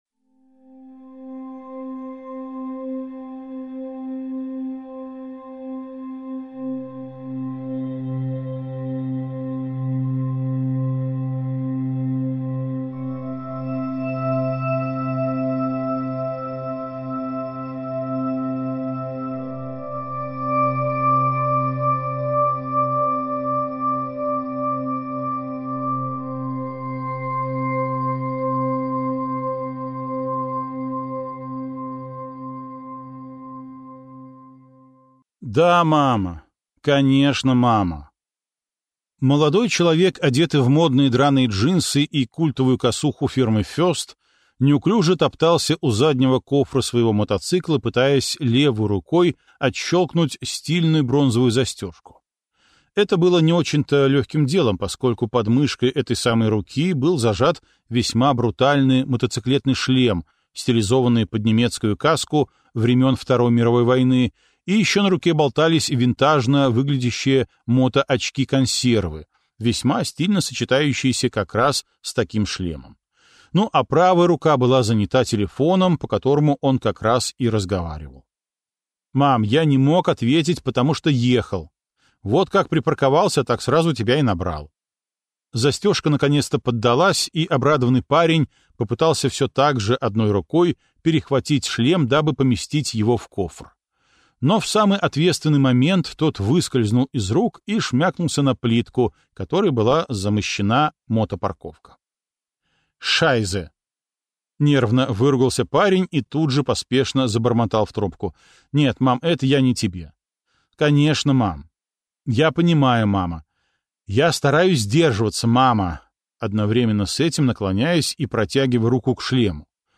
Аудиокнига Швейцарец - купить, скачать и слушать онлайн | КнигоПоиск